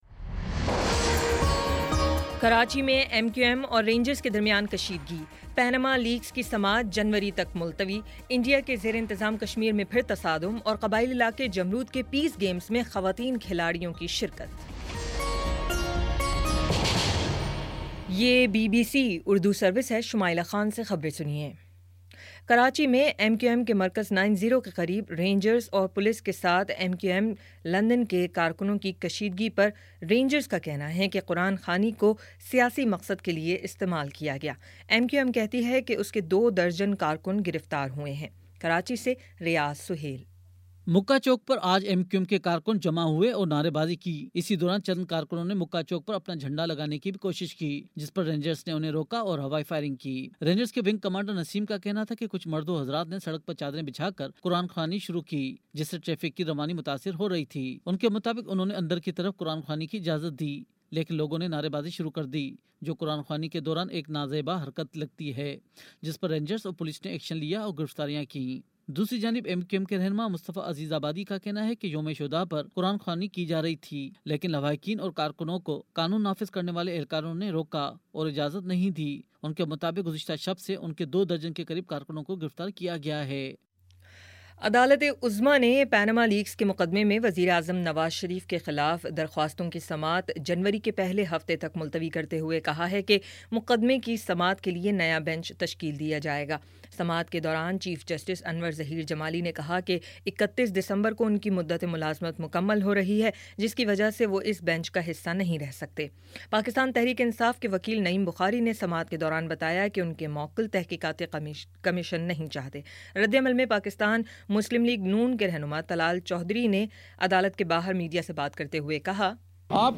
دسمبر09 : شام پانچ بجے کا نیوز بُلیٹن